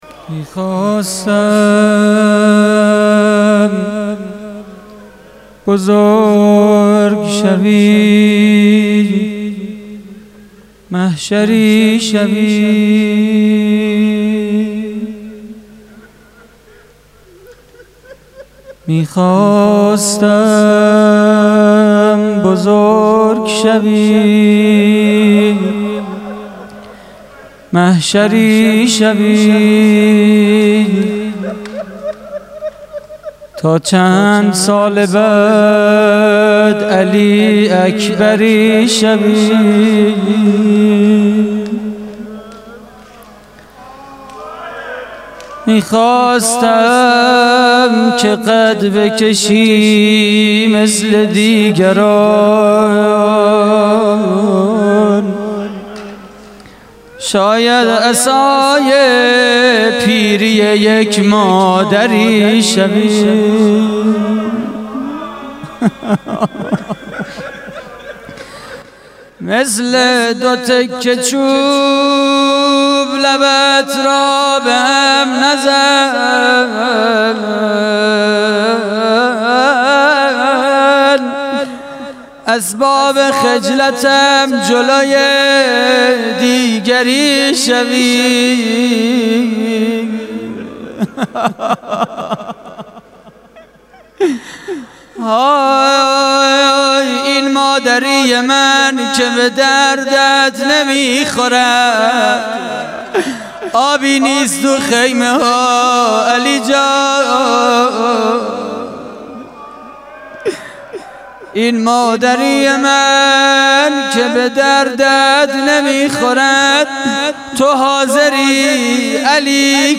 مناجات سحری ماه رمضان / هیئت الزهرا (س) دانشگاه صنعتی شریف - تیر 93
روضه: می‌خواستم بزرگ شوی (توسل به حضرت علی اصغر ع)؛ پخش آنلاین |